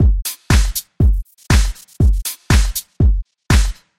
kick hat tinny snare 120bpm " hi hat 120bpm0105
描述：hihat循环120bpm